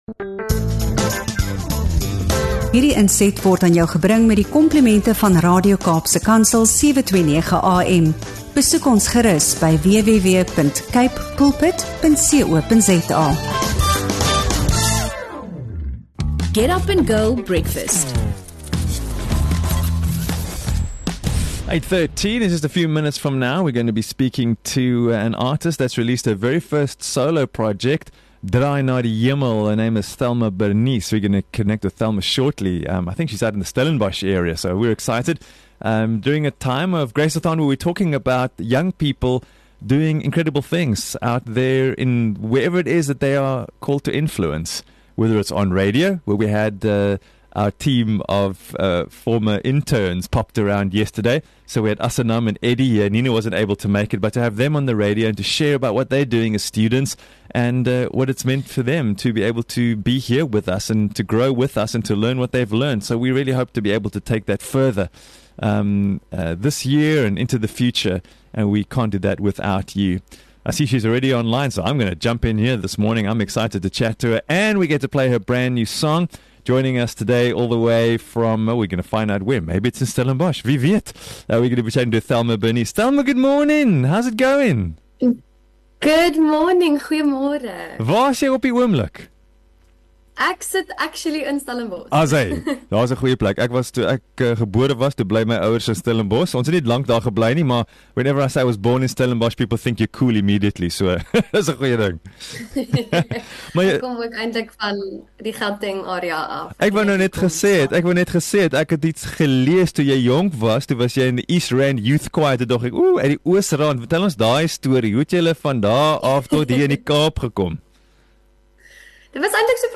Luister na ‘n inspirerende gesprek